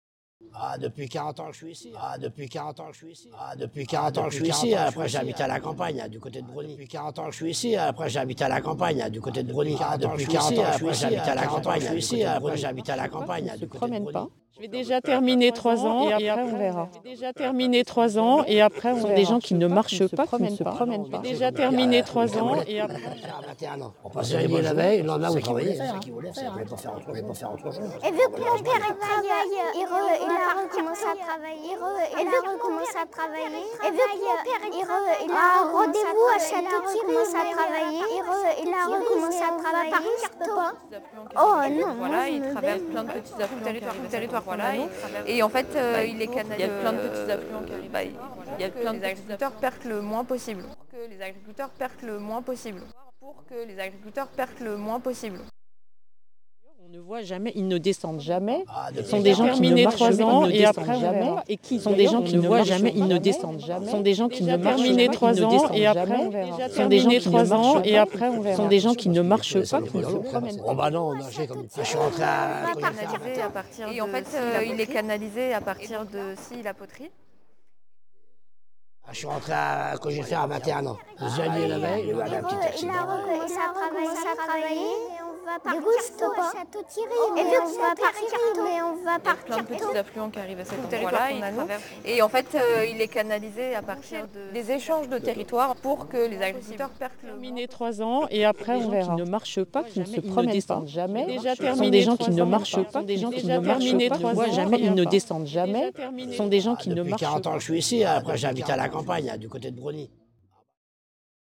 Avec les patches PureData ExMM93-PlayGrain2-3